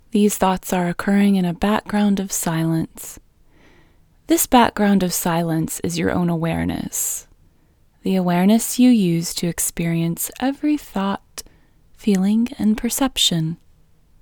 QUIETNESS Female English 5
Quietness-Female-5-1.mp3